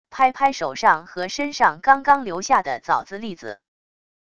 拍拍手上和身上刚刚留下的枣子栗子wav音频